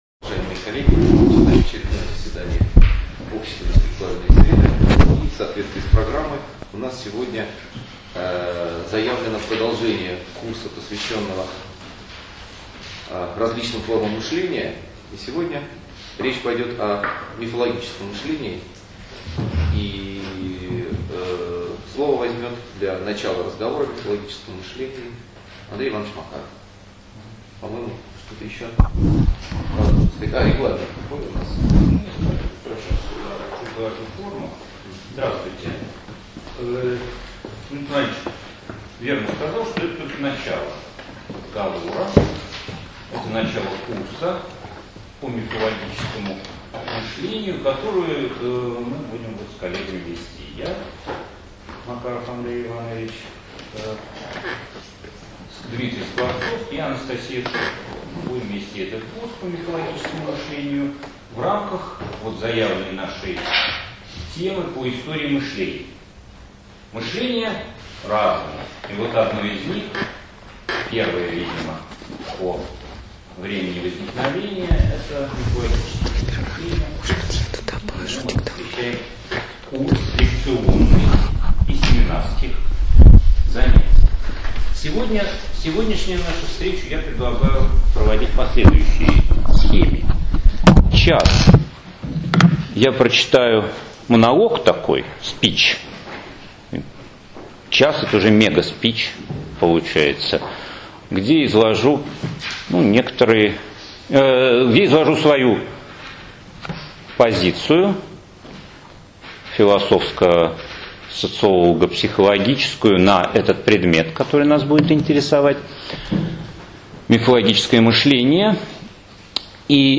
Аудиокнига Мифологическое мышление | Библиотека аудиокниг